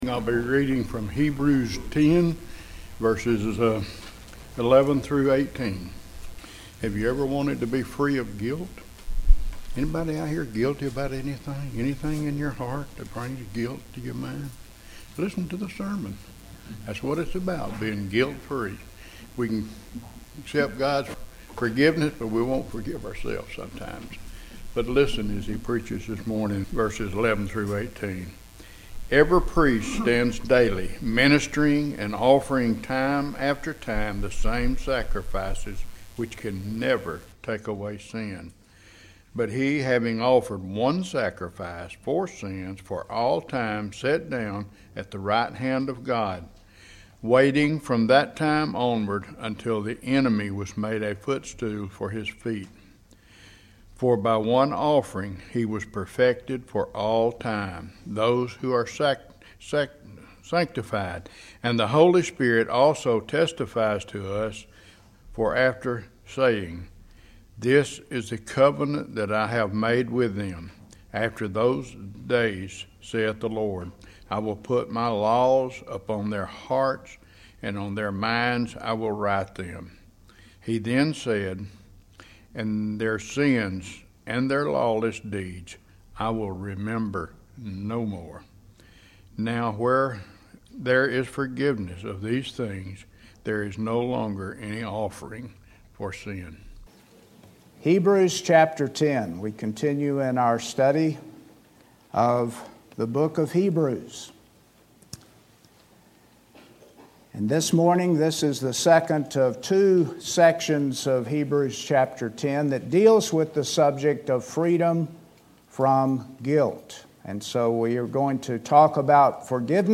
McCalla Bible Church Sermons